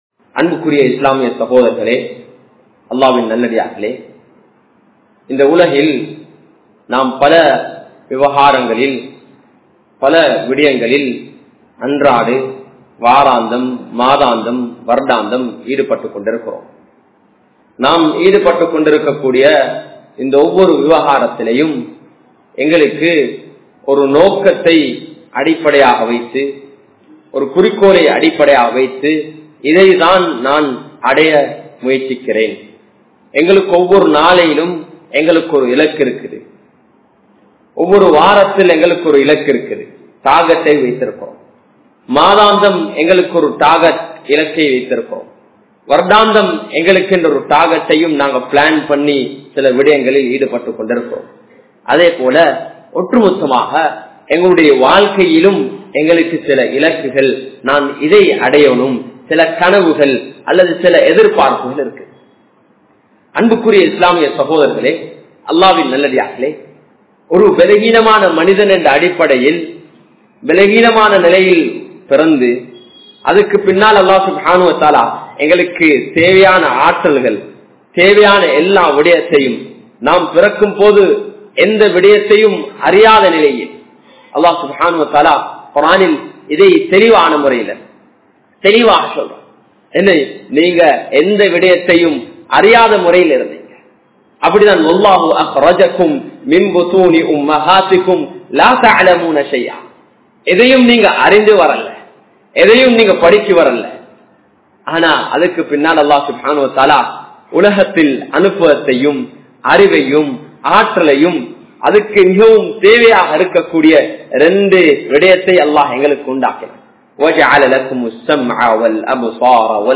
Padaithavan Emmidam Ethir Paarpavai (படைத்தவன் எம்மிடம் எதிர்பார்ப்பவை) | Audio Bayans | All Ceylon Muslim Youth Community | Addalaichenai
Samman Kottu Jumua Masjith (Red Masjith)